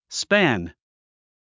発音
spǽn　スパン